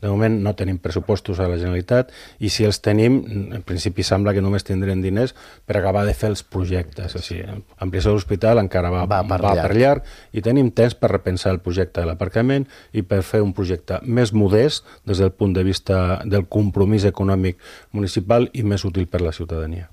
Així ho ha assegurat el portaveu del partit, Sebastian Tejada, a l’espai de l’entrevista política de Ràdio Calella TV d’aquesta setmana, on també ha repassat altres temes d’actualitat com el projecte del futur aparcament de Can Saleta, àmbit en el qual insisteix a reclamar una solució alternativa.